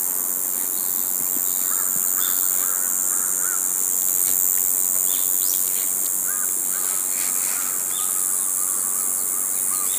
Xestophyrys javanicus